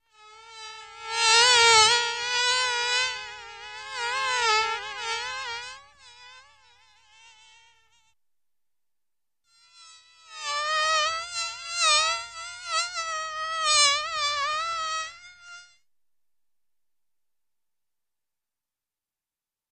Swarm Of Insects Buzzing Around